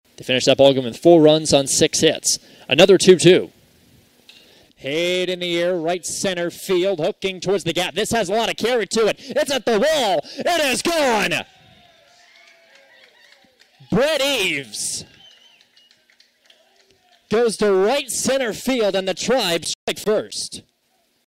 Indians baseball split their doubleheader on Friday in Forsyth, Missouri.